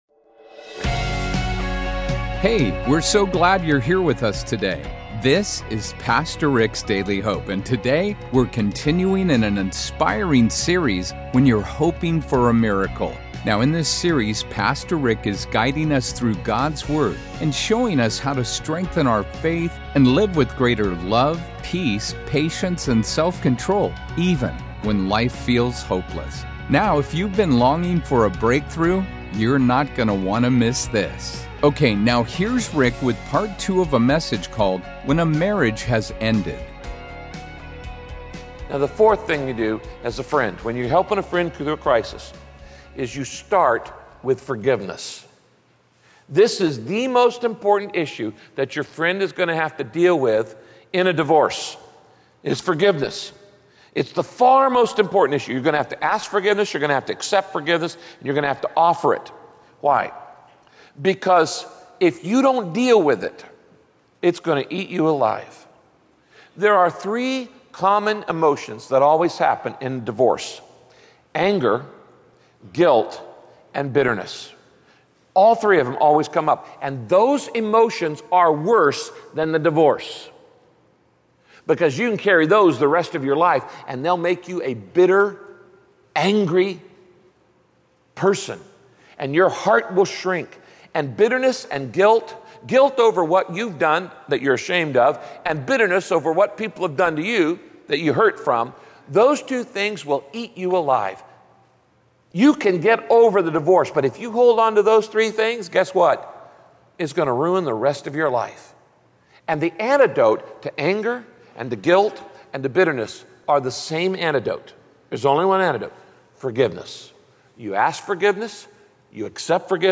When a friend’s marriage has ended, they need you to help carry the load and show compassion for their pain. In this broadcast, Pastor Rick explains how to show…